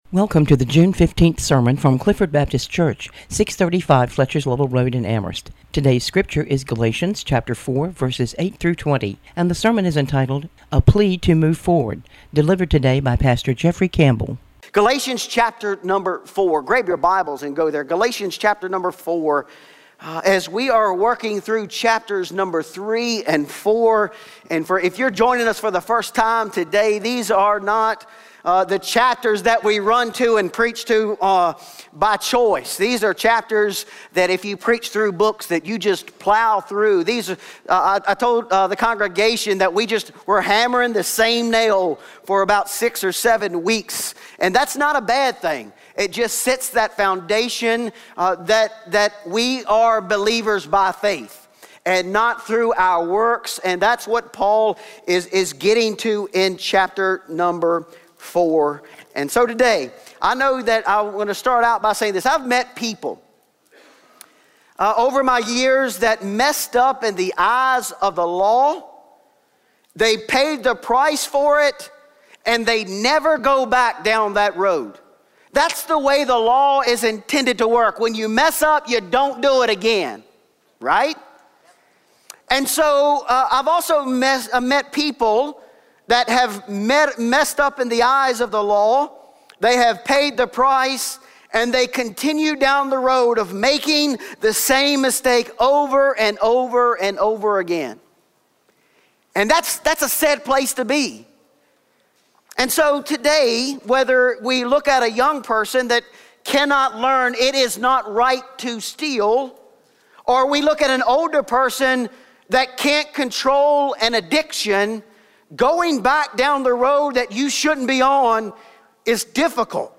Paul is writing to the Galatian churches urging them not to return to the former means of spiritual slavery. He is reminding them of their past love for the Gospel and encouraging them to continue to move forward in their faith. Join us for the message today that reminds us of distractions and encourages us to continue to walk with Jesus.